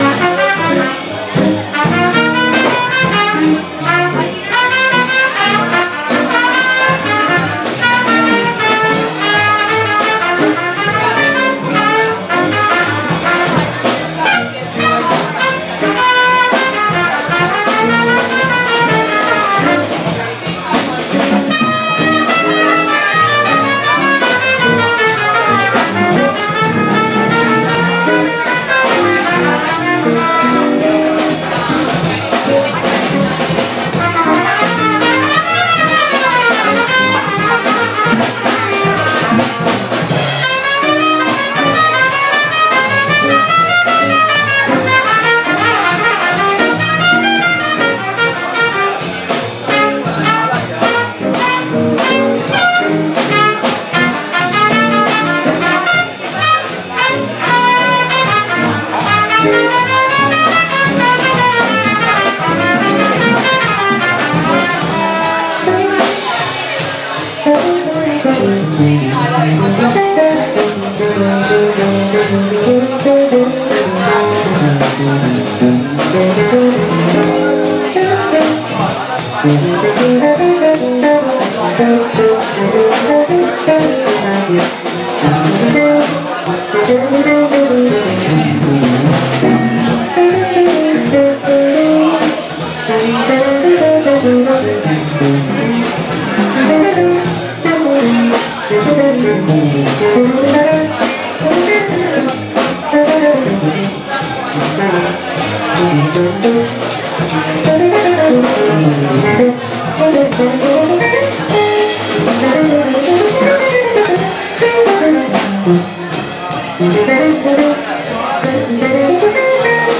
The Effra Hall Wednesday night jazz band in action